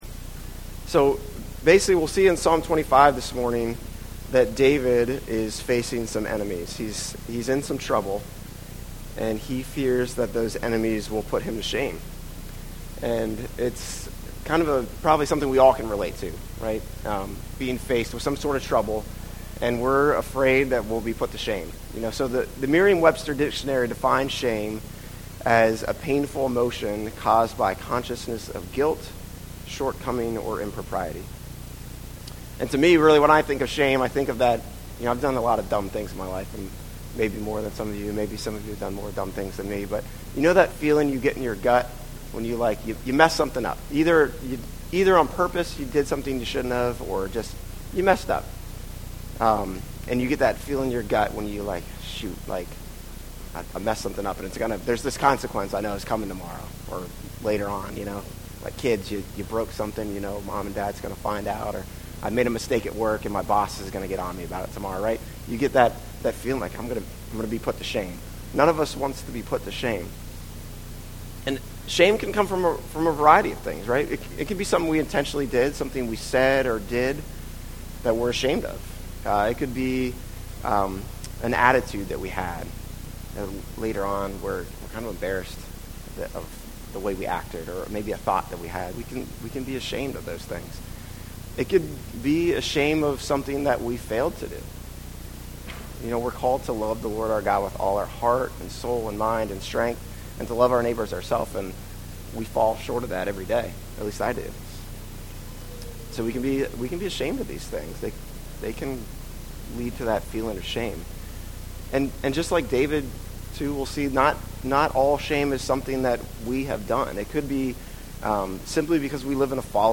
Sermons | James River Community Church